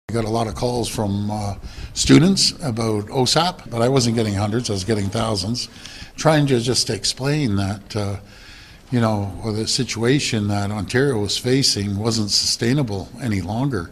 He spoke about this on Tuesday with reporters.